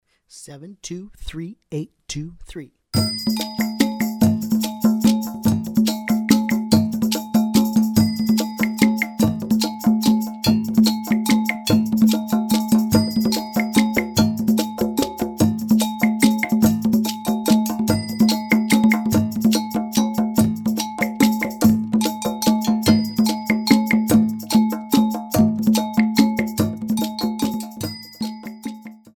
Medium Triple Meter - 145 bpm